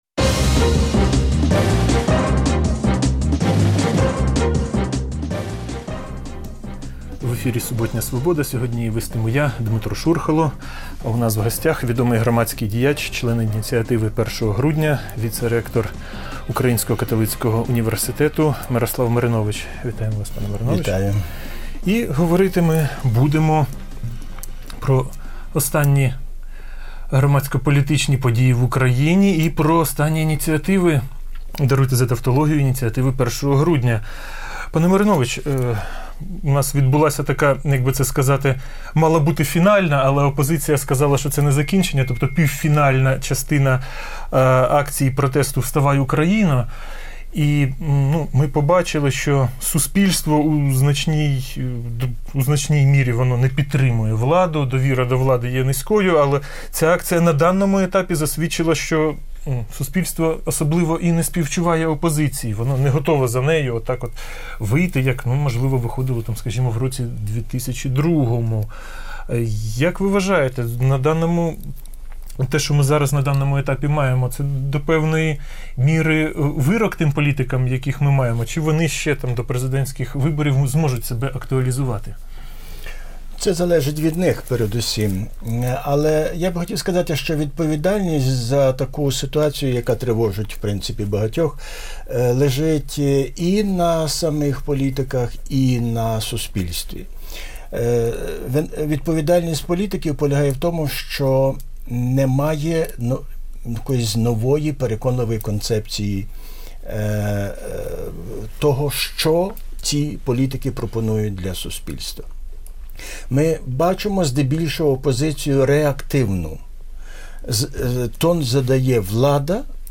Суботнє інтерв'ю